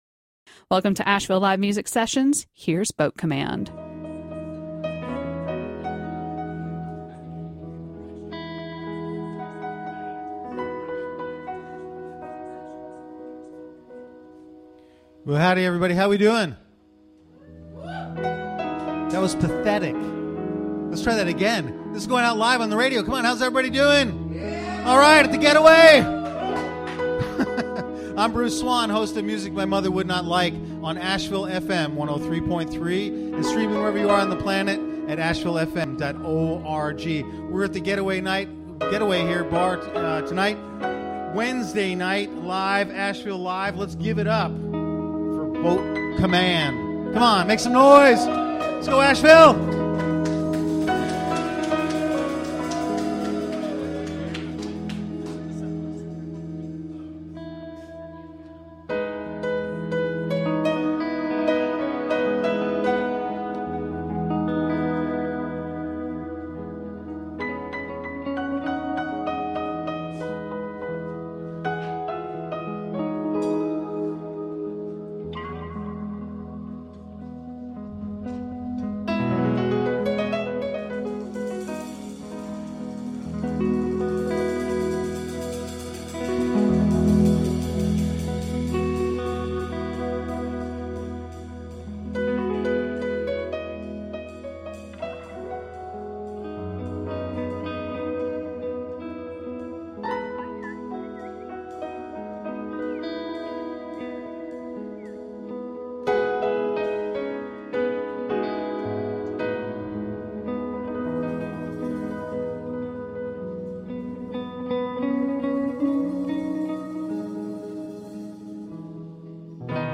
Live from The Getaway River Bar
Recorded during safe harbor – Explicit language warning